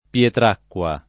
[ p L etr # kk U a ]